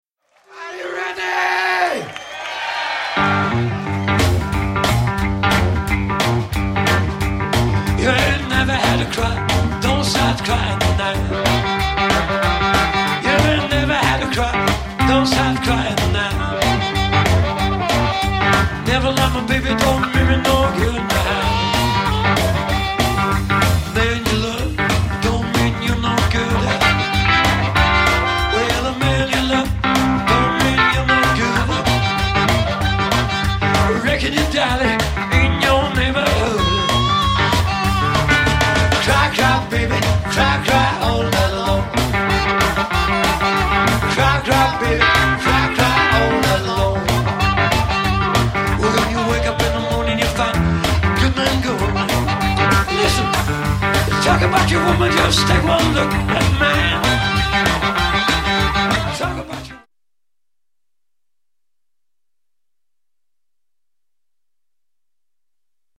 cajon & drums
8 to the bar: Live im Live
Schon lange fragen uns Fans nach einer Live-CD.
repräsentieren: Country-und Big City Blues, Soul,
Roots Music und mehr - jedoch nicht schematisch
drei Musikern.